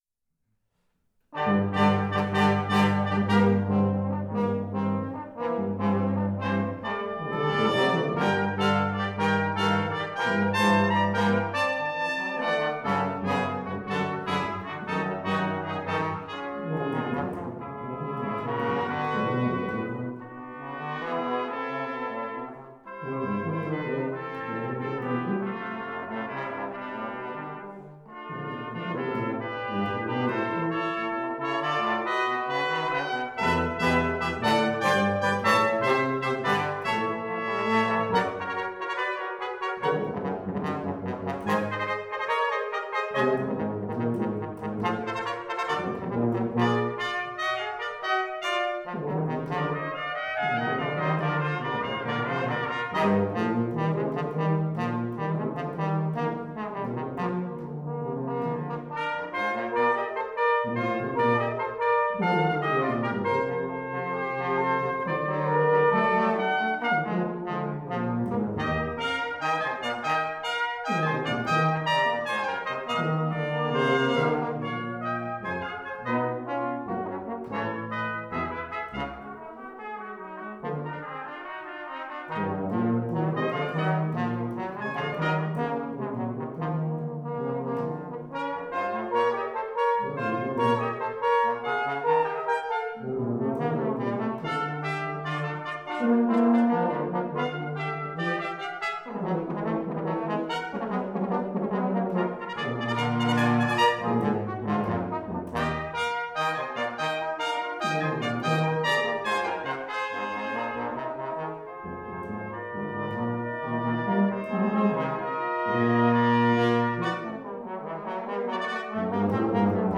• Action (00:00);
• Adventure (00:00);
• Western (00:00)